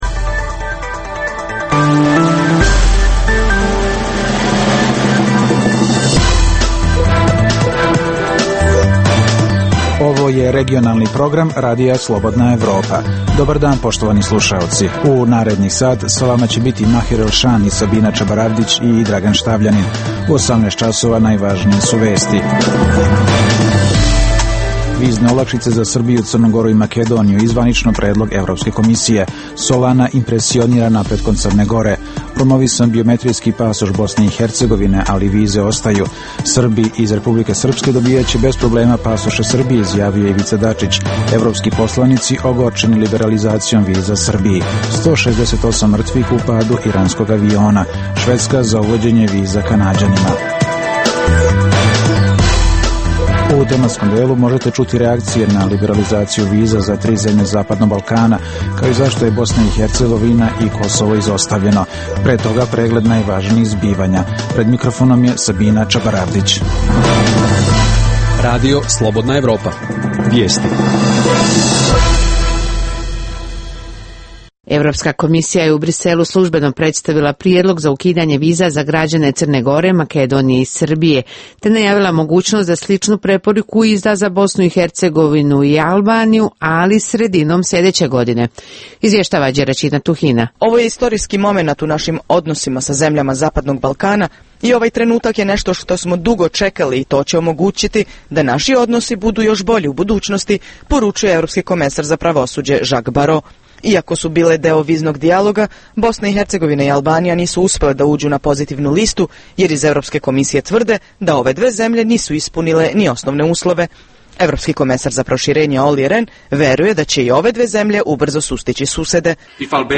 Tragamo za odgovorom na pitanje - šta se to događa na jugu Srbije? Nadbiskup vrhbosanski kardinal Vinko Puljić u intervjuu za naš radio govori o položaju Hrvata u BiH.